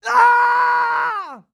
Male_Death_Shout_04.wav